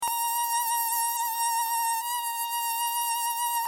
دانلود صدای حشره 17 از ساعد نیوز با لینک مستقیم و کیفیت بالا
جلوه های صوتی